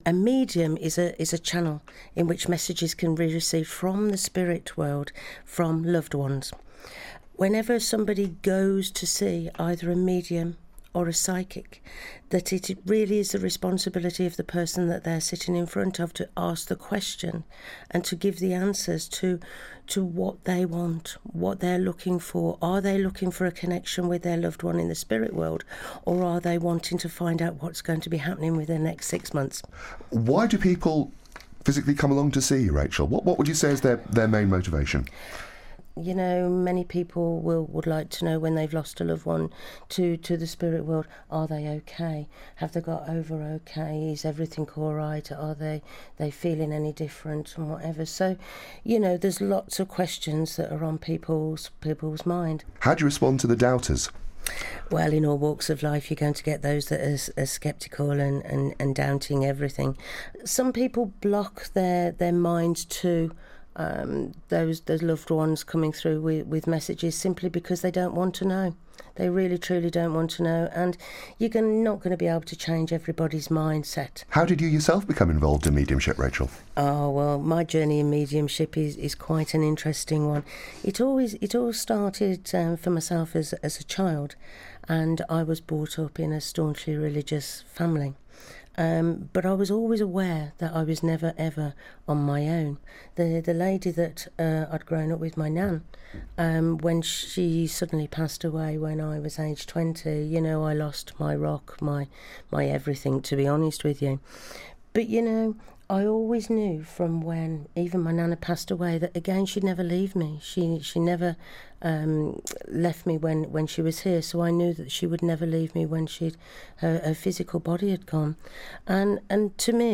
Interviews A Medium